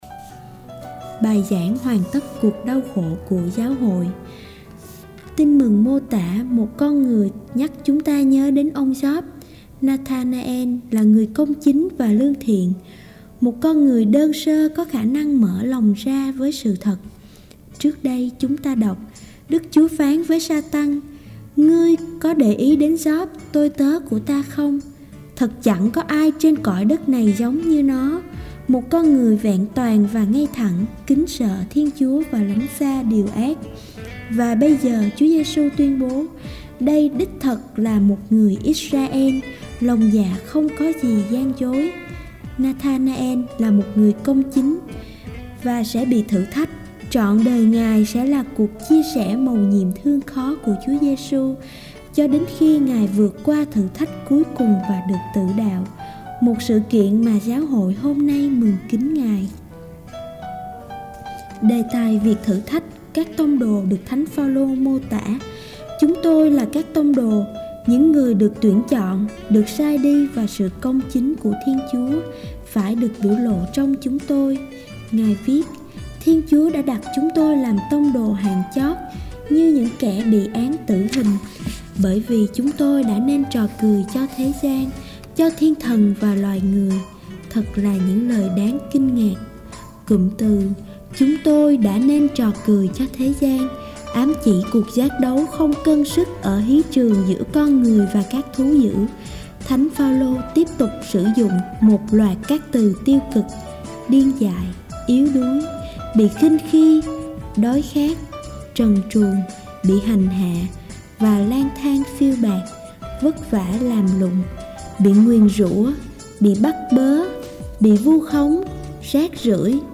12. Bai giang  hoan tat cuoc dau kho cua Giao hoi.mp3